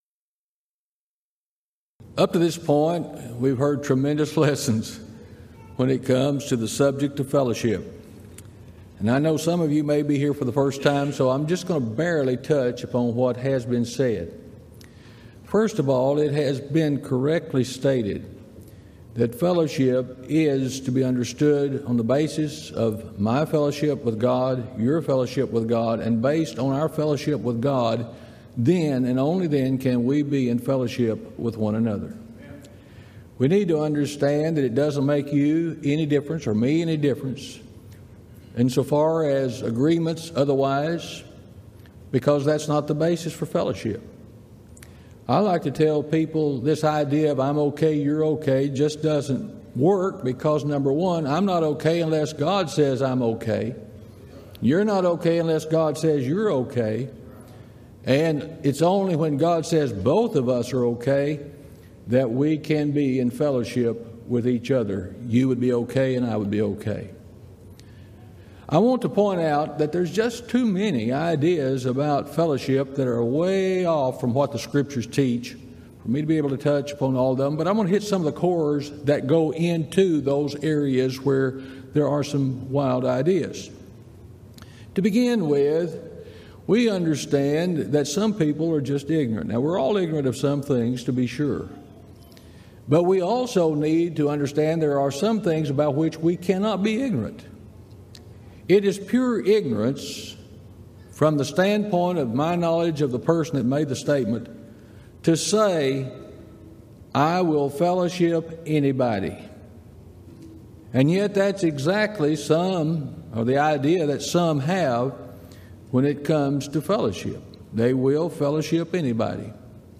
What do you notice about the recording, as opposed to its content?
Event: 24th Annual Gulf Coast Lectures Theme/Title: Christian Fellowship